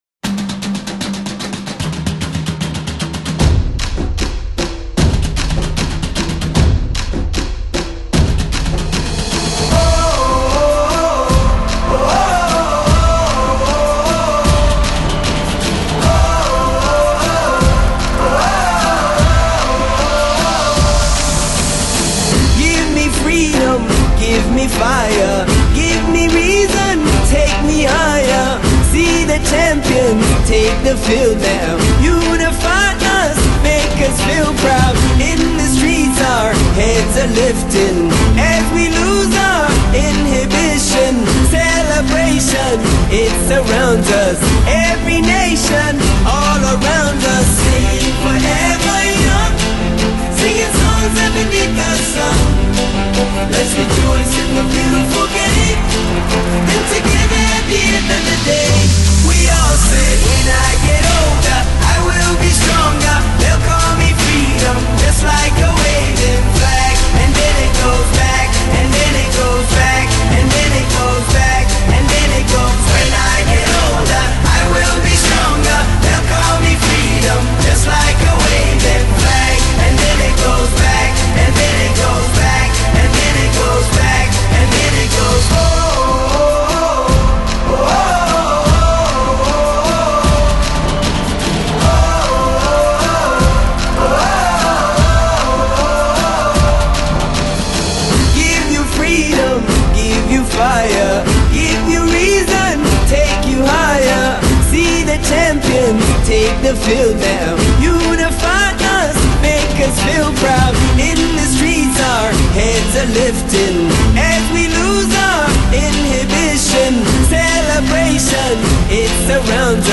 Pop, Dance, RnB
歌曲带有浓郁的非洲气息